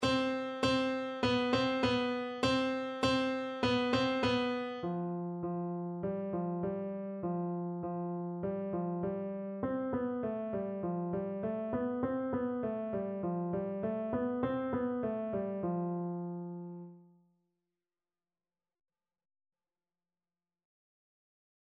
2/4 (View more 2/4 Music)
Beginners Level: Recommended for Beginners
Piano  (View more Beginners Piano Music)
Classical (View more Classical Piano Music)